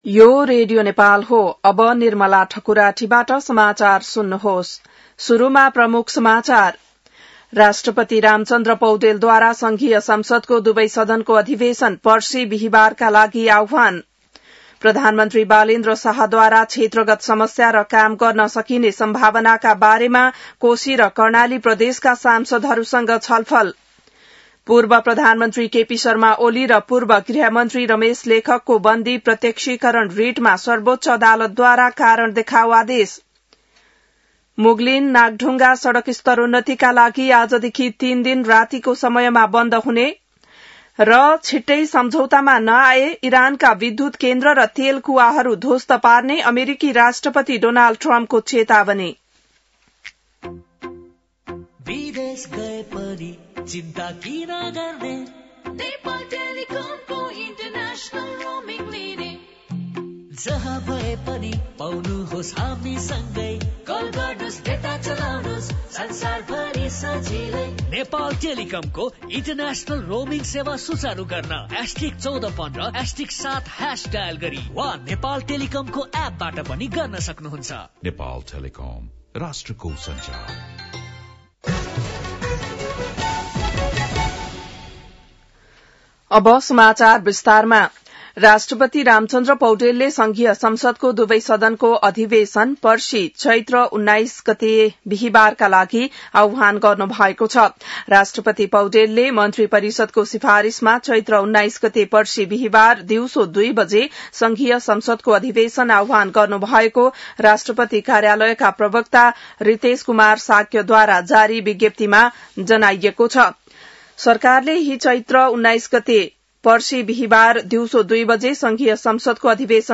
बिहान ७ बजेको नेपाली समाचार : १७ चैत , २०८२